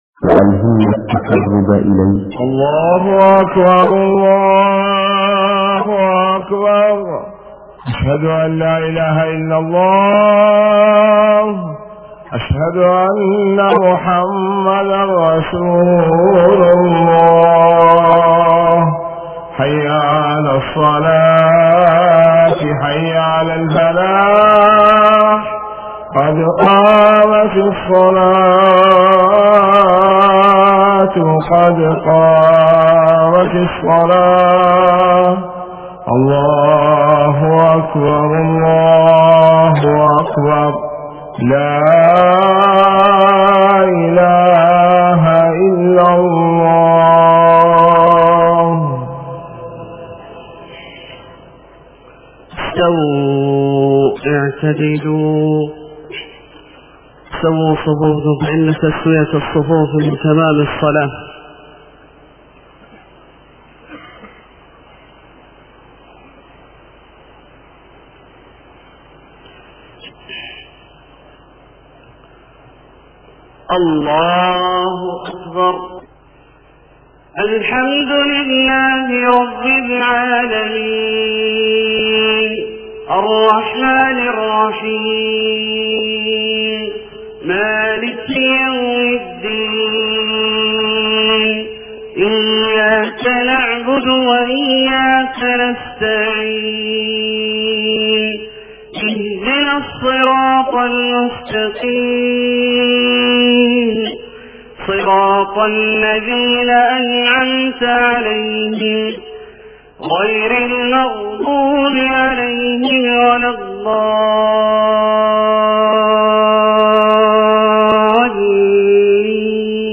صلاة الفجر 7-7-1428هـ من سورة المائدة {110-120} > 1428 🕋 > الفروض - تلاوات الحرمين